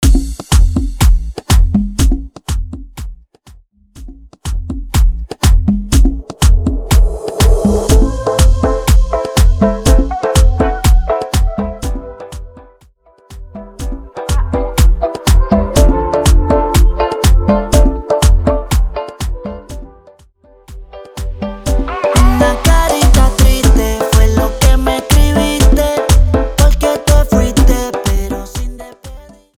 Intro Dirty